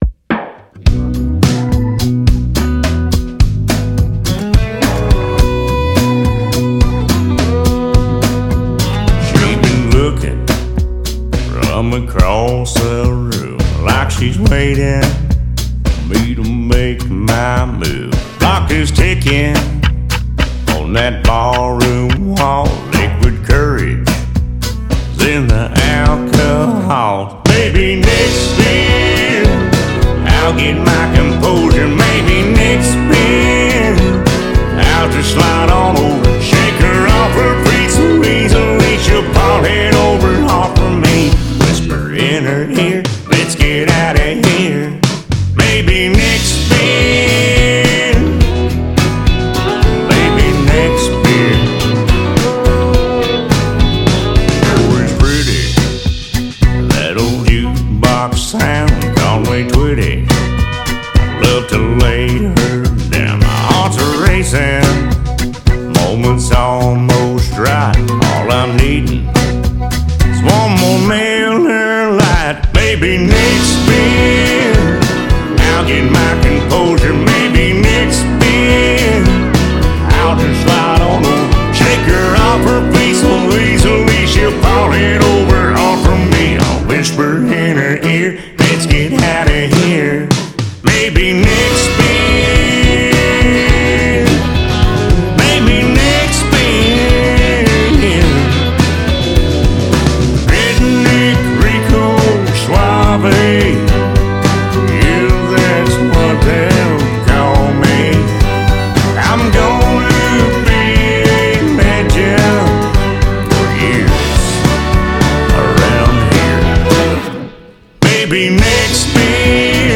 ENTERTAINER • SONGWRITER • RANCHER